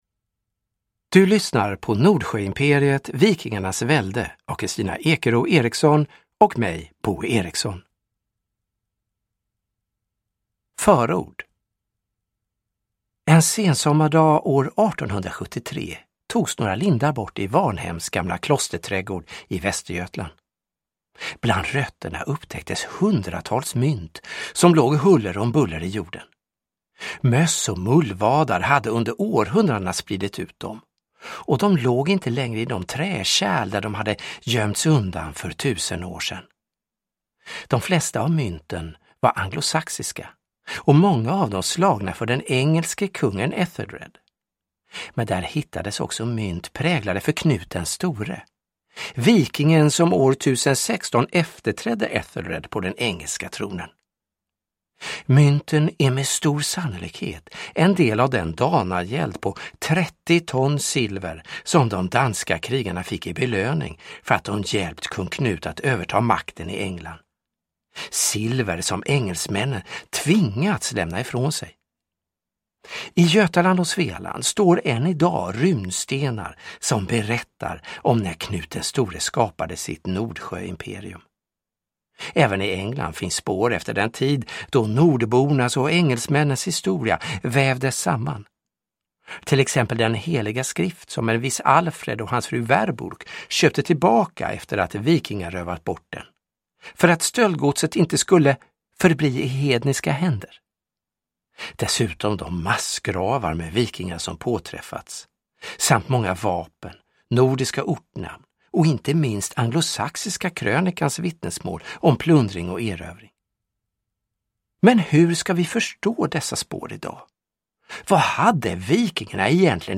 Nordsjöimperiet : vikingarnas välde – Ljudbok